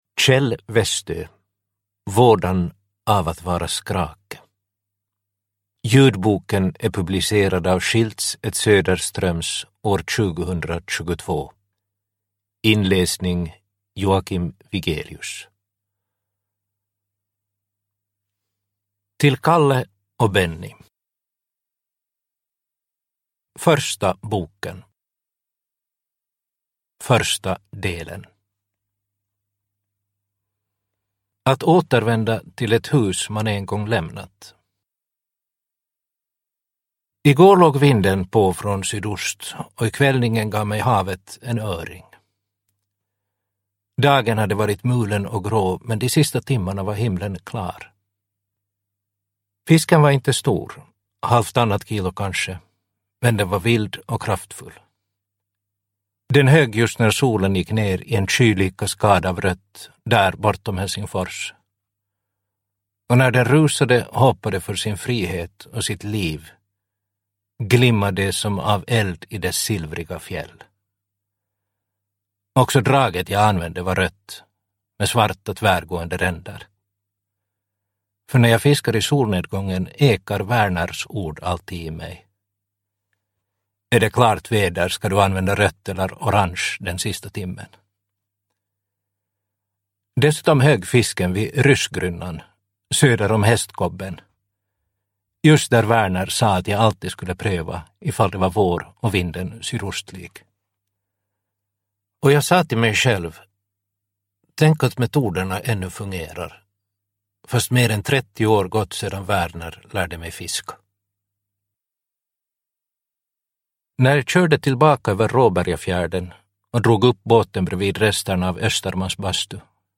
Vådan av att vara Skrake – Ljudbok – Laddas ner